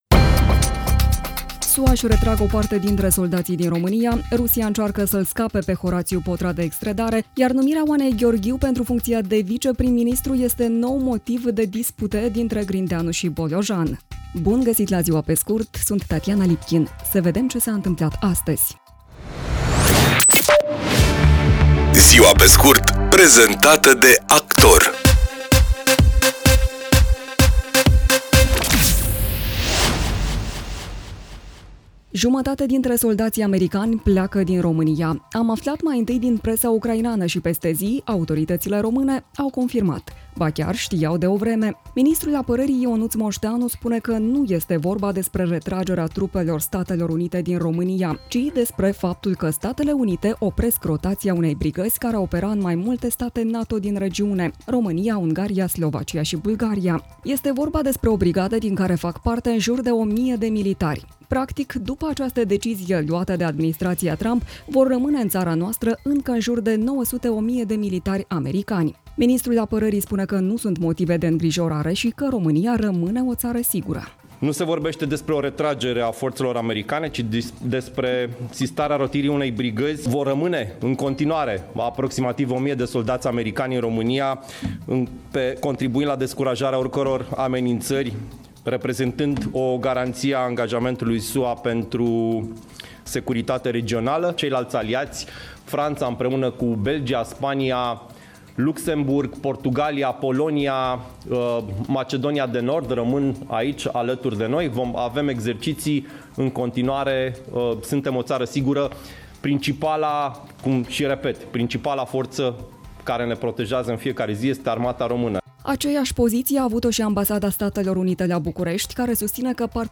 „Ziua pe scurt” este un podcast zilnic de actualitate care oferă, în doar zece minute, o sinteză a principalelor cinci știri ale zilei. Formatul propune o abordare prietenoasă, echilibrată și relaxată a informației, adaptată publicului modern, aflat mereu în mișcare, dar care își dorește să rămână conectat la cele mai importante evenimente.